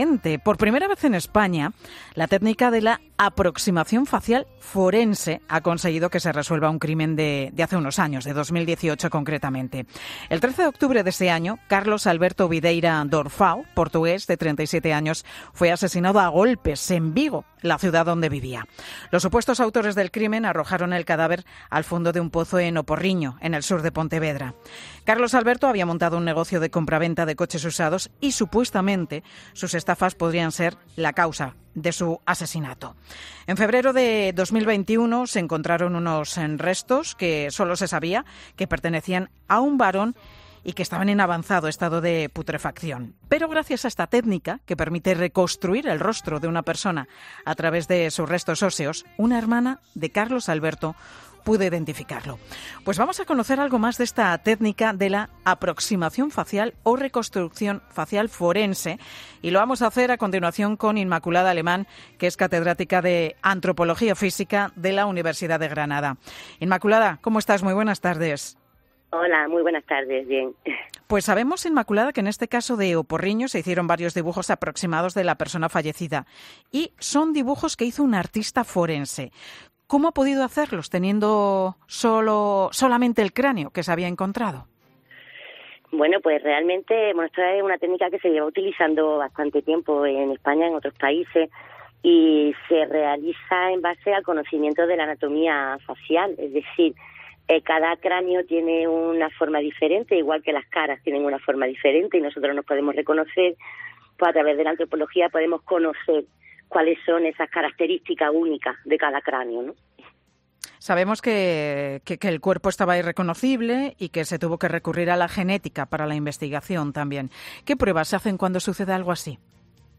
"Se fijan en si la cara es más alta, más estrecha, ancha, baja... Cómo están ubicados los ojos, la nariz, la boca...", dice la entrevistada sobre en qué se fijan los investigadores para reconstruir el rostro.